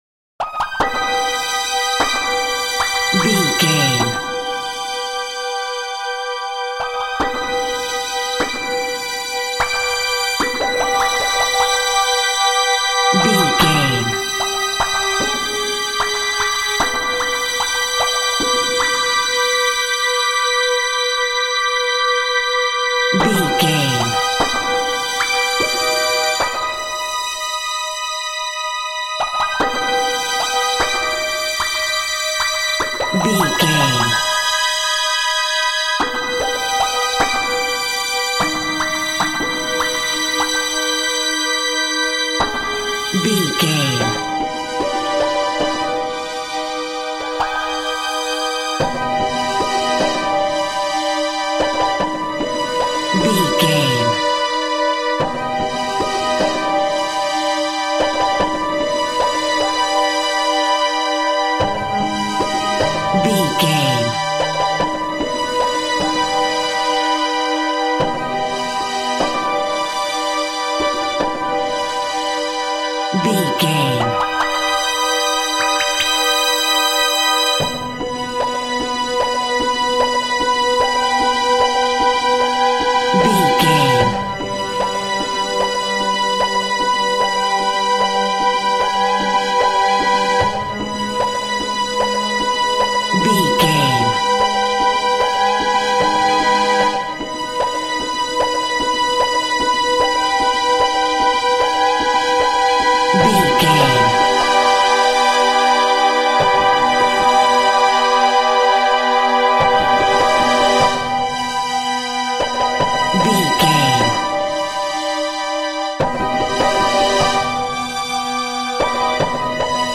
In-crescendo
Aeolian/Minor
tension
ominous
dark
eerie
Horror Ambience
electronics
synthesizer